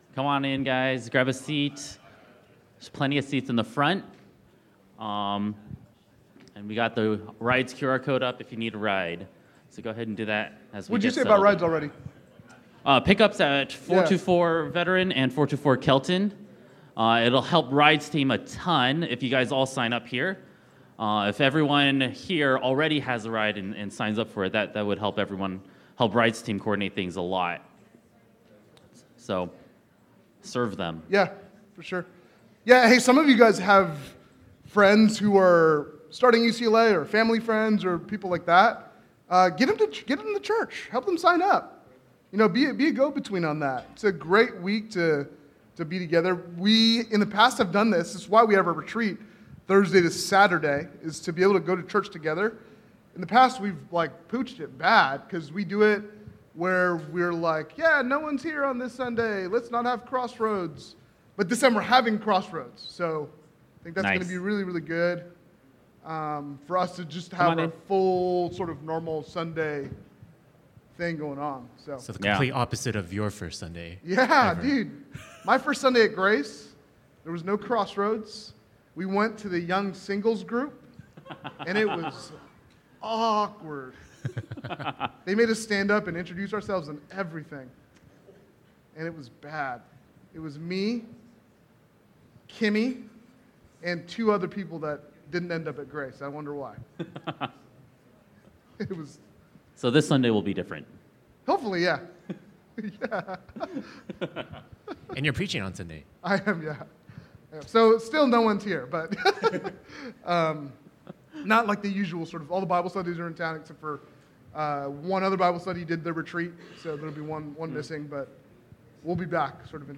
Fall Retreat Q&A